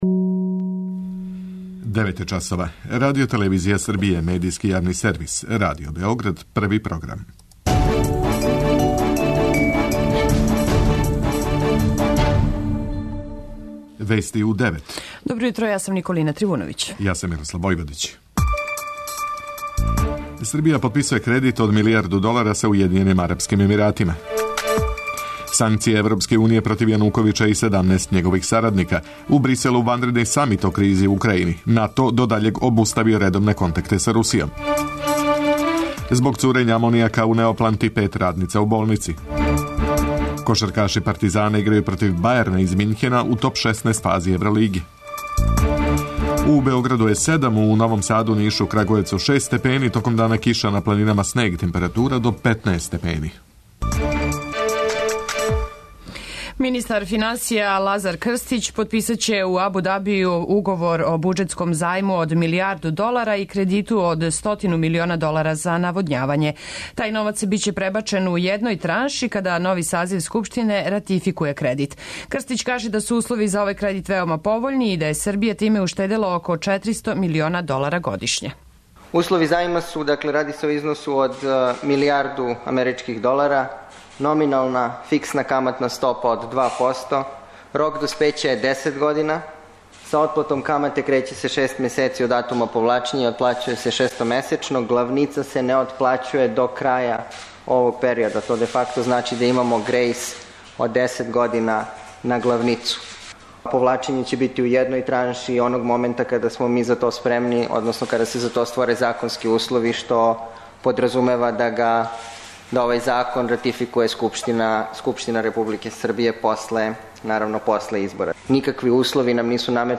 преузми : 9.82 MB Вести у 9 Autor: разни аутори Преглед најважнијиx информација из земље из света.